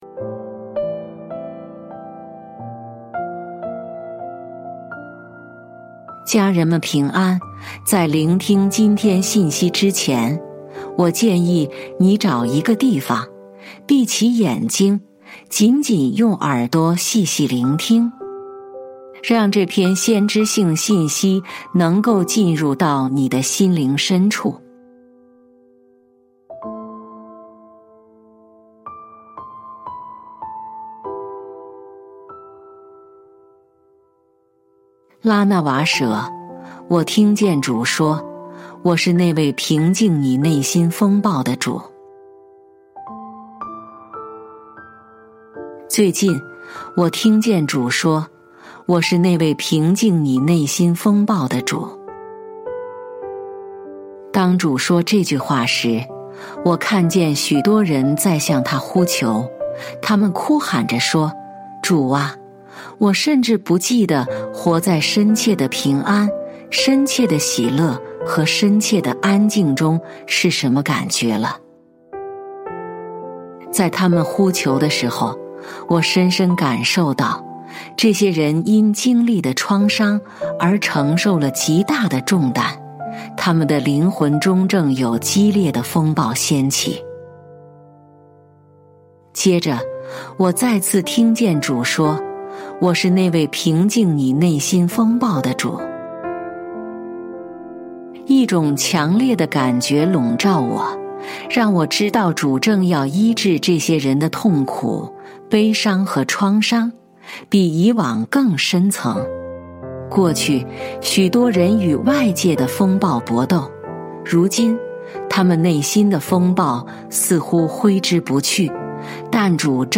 本篇是由拉娜瓦舍授权，由微牧之歌翻译并以第一人称 我 ，来为大家朗读。